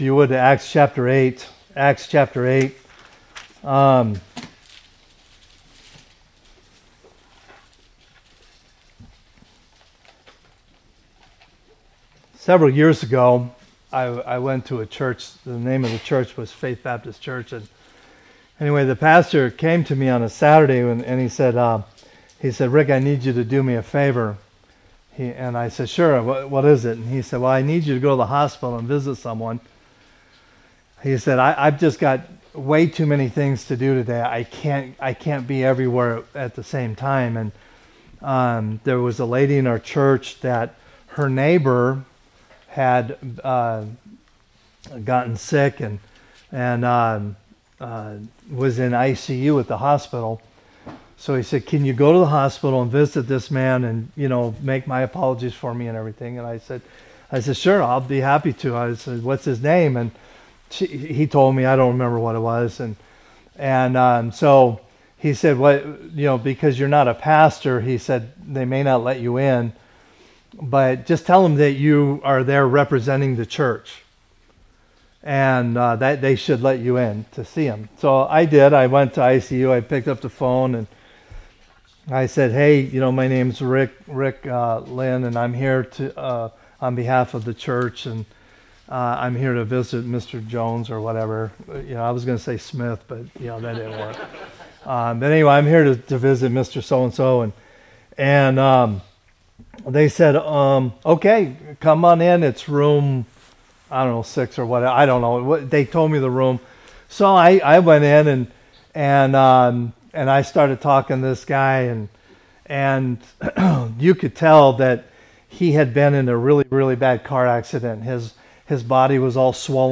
A message from the series "General Series."